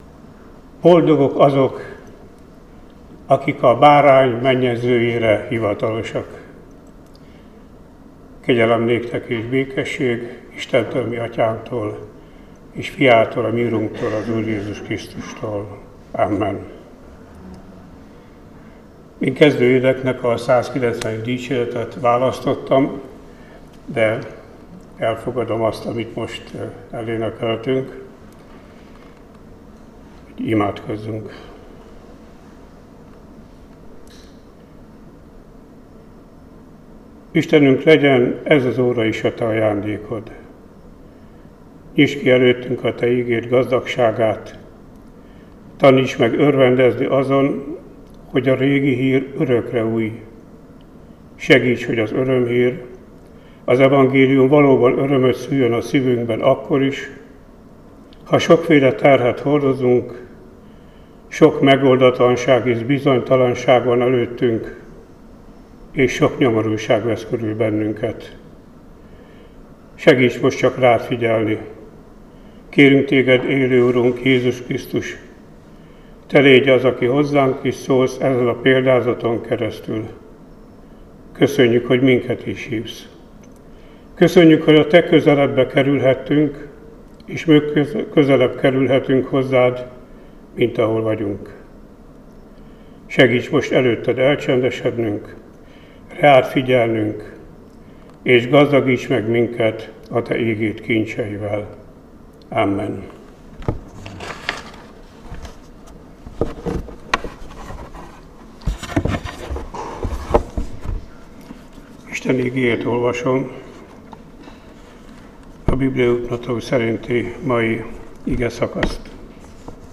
Áhítat, 2024. szeptember 17.